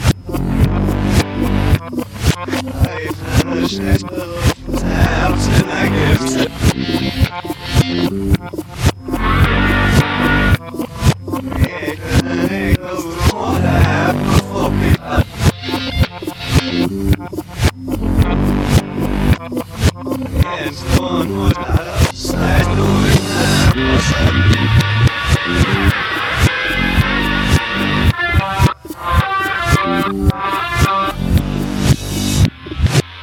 extrait inversé.